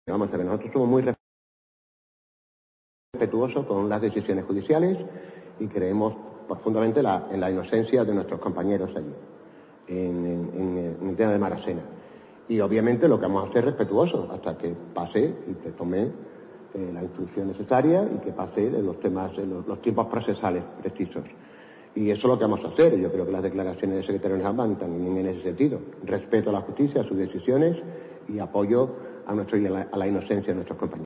Coordinador del Comité Electoral del PSOE-A, Antonio Gutiérrez Limones
En una rueda de prensa en la sede regional del PSOE-A, Antonio Gutiérrez Limones se ha pronunciado así a preguntas de los periodistas sobre la posibilidad de que el PSOE adoptara alguna decisión judicial después de que el Tribunal Superior de Justicia de Andalucía (TSJA) acordase la semana pasada devolver al Juzgado de Instrucción número 5 de Granada la causa de dicho secuestro y no incoar diligencias previas de investigación penal contra Noel López, al entender que no se han agotado las necesarias diligencias de investigación que permitan afirmar con "claridad", en este momento de la investigación, los hechos que se le atribuyen en relación a este asunto.